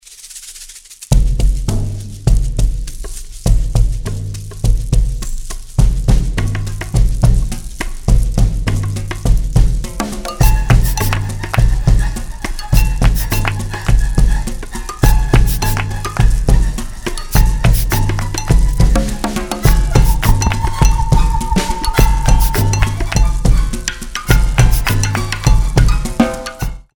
piano and percussion music